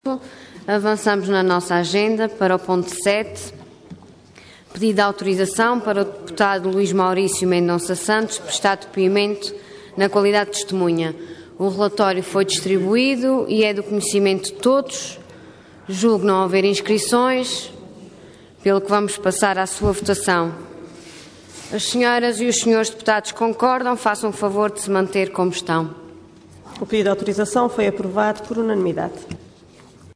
Assembleia Legislativa da Região Autónoma dos Açores
Intervenção
Presidente da Assembleia Regional